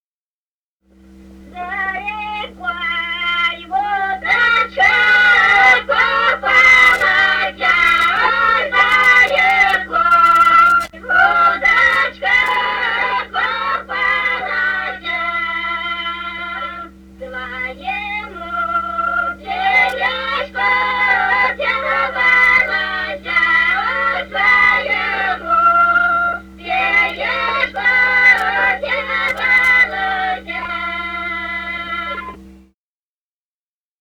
Русские народные песни Красноярского края.